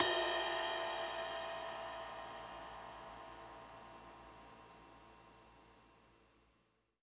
Closed Hats
Ride_4.wav